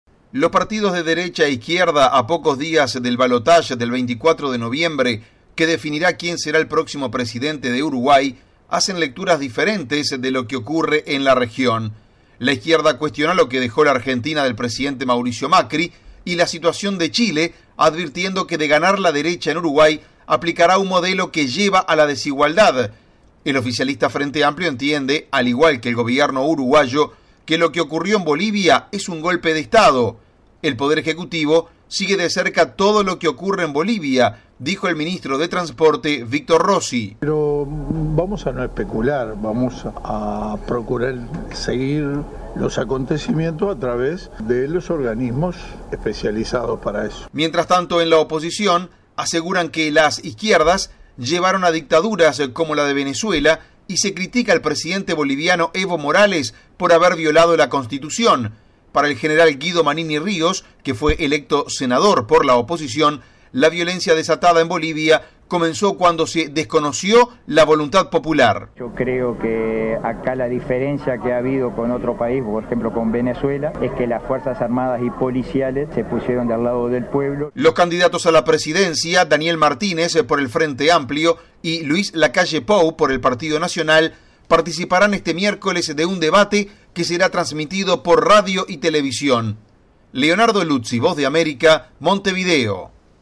VOA: Informe desde Uruguay